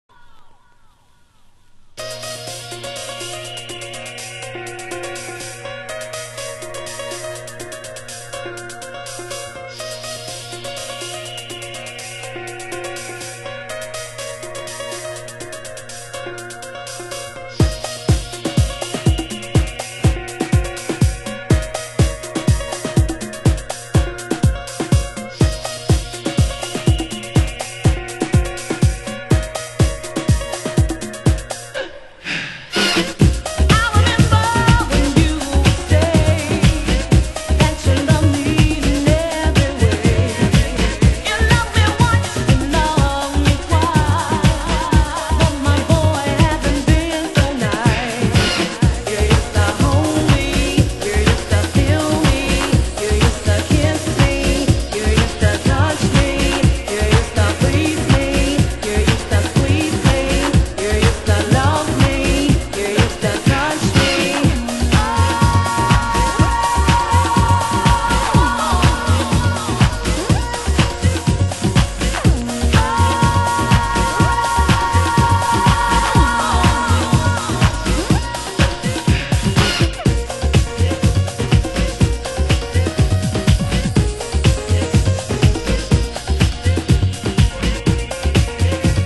盤質：軽いスレ、小傷、少しチリパチノイズ有　　　ジャケ：スレ有